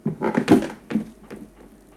Abrir un cubo de basura
Sonidos: Acciones humanas
Sonidos: Hogar